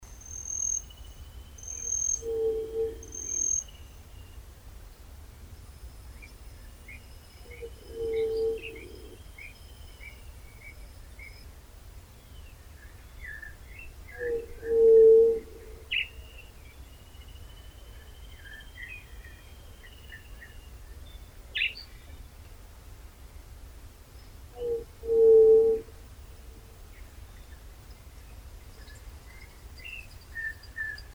Yerutí Común (Leptotila verreauxi)
131026yeruti-comun.MP3
Nombre en inglés: White-tipped Dove
Fase de la vida: Adulto
Localidad o área protegida: Reserva Privada y Ecolodge Surucuá
Condición: Silvestre
Certeza: Vocalización Grabada